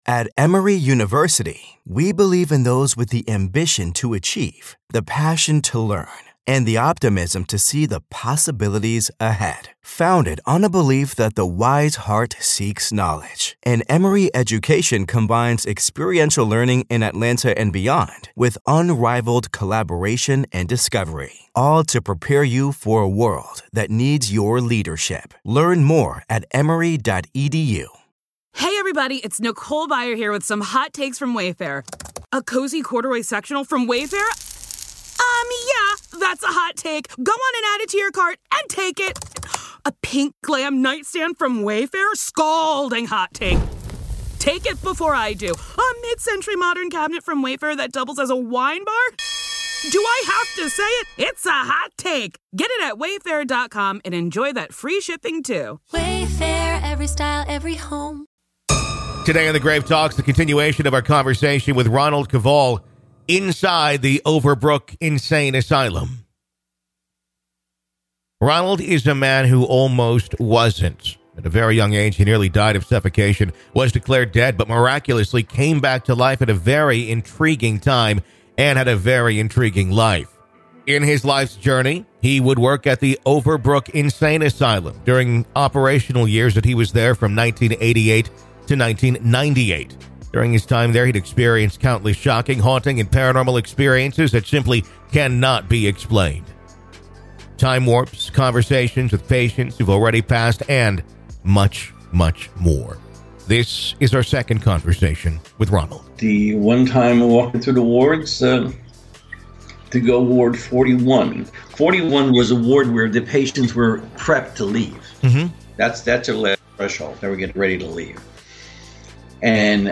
From time warps to conversations with patients who have passed, and much more. This is Part Three of our conversation.